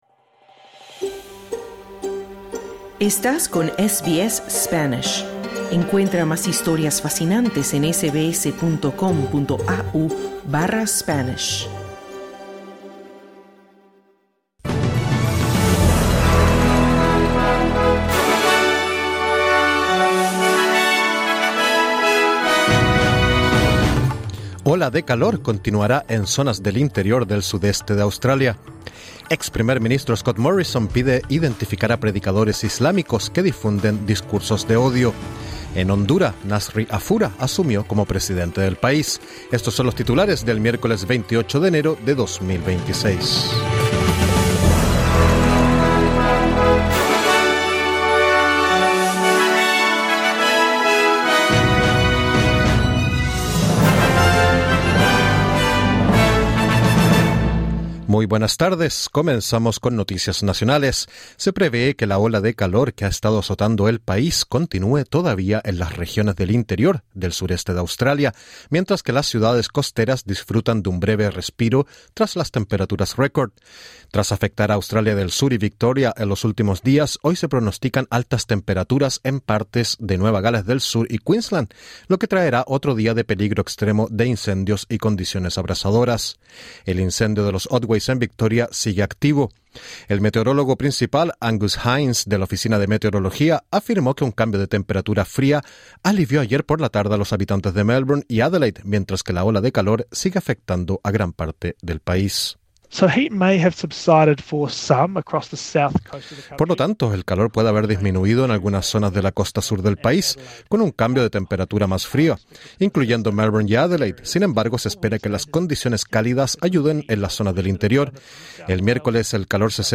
Boletín de noticias 28/01/26: Desde la Oficina de Meteorología afirman que ola de calor continuará azotando zonas interiores del sureste de Australia durante los próximos días. Ex primer ministro Scott Morrison pide que imánes musulmanes se registren en Australia para evitar la divulgación de discursos de odio.